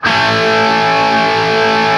TRIAD A  L-R.wav